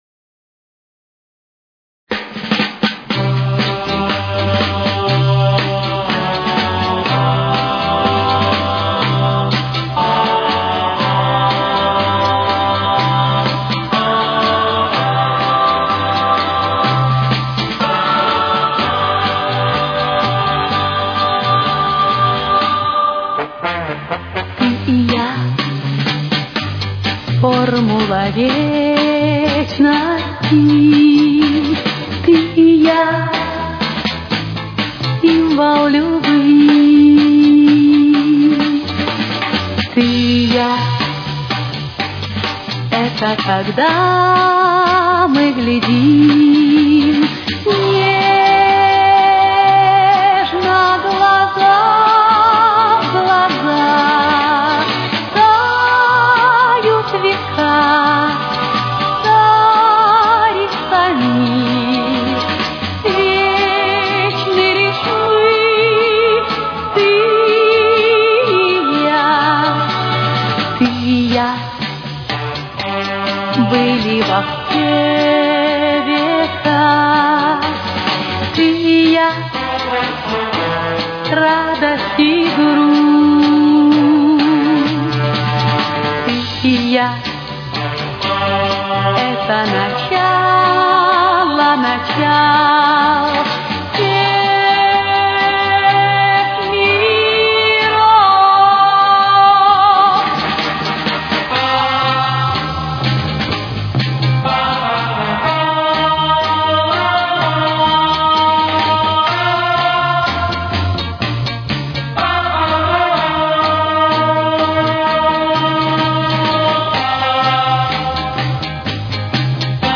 с очень низким качеством (16 – 32 кБит/с)
Соль мажор. Темп: 127.